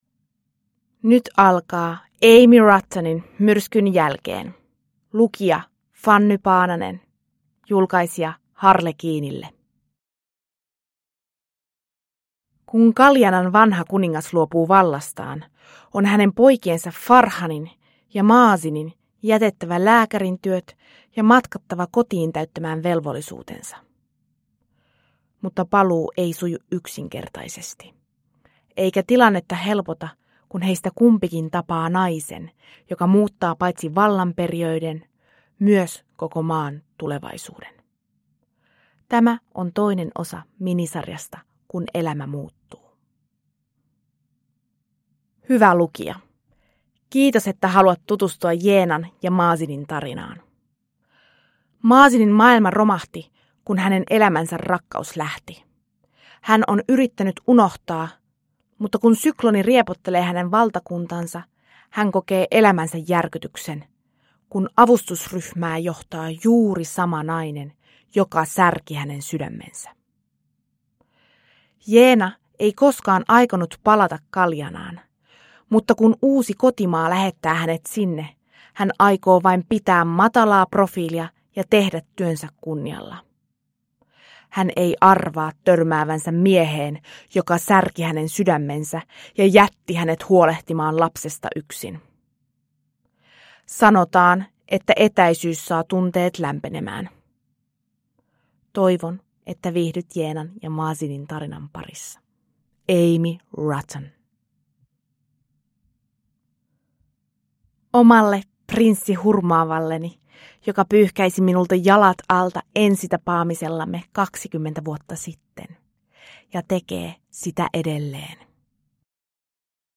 Myrskyn jälkeen – Ljudbok – Laddas ner